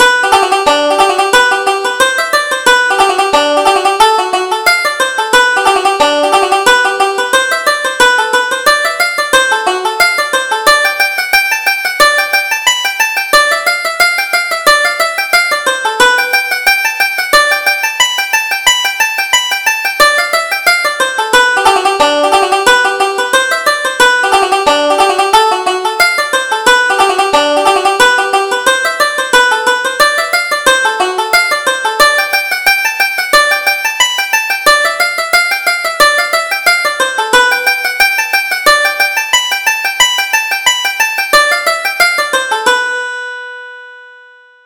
Reel: The Dogs Among the Bushes